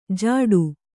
♪ jāḍu